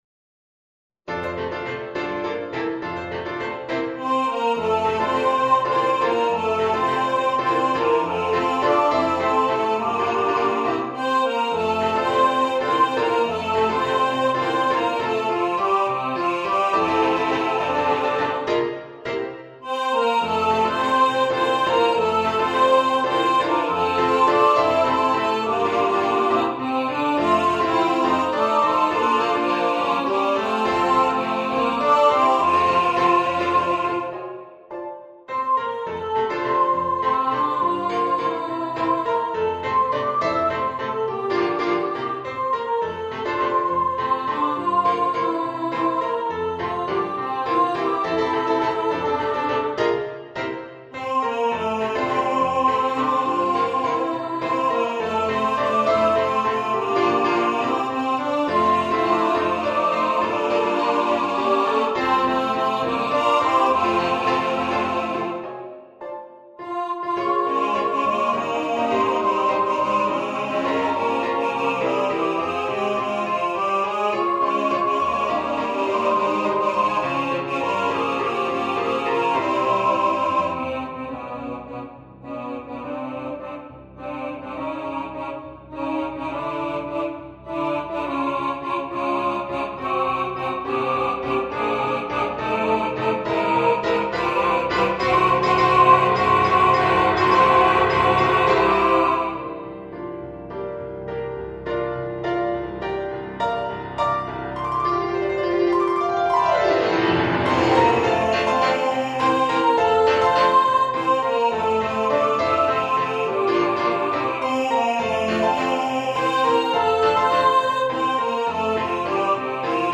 for SA+Men choir
SA+Men choir with accompanmiment of piano or orchestra.